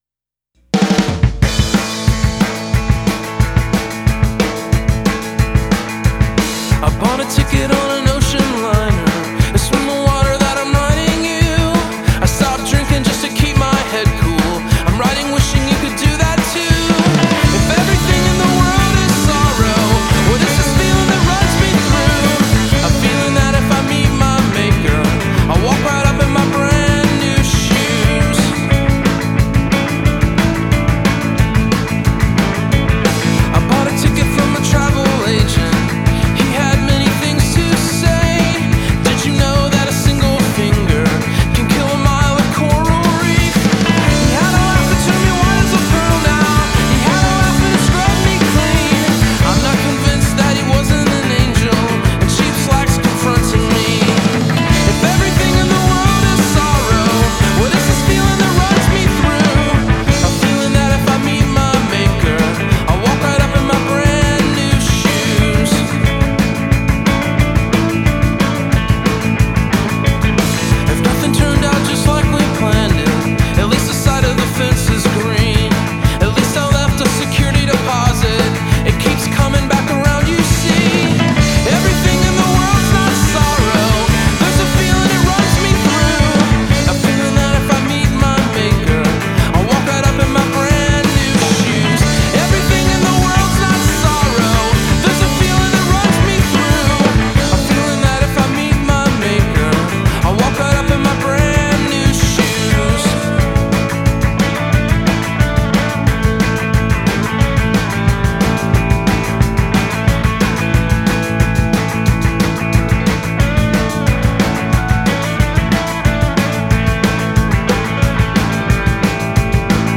gtr, vox
bass
drums
(keyboards).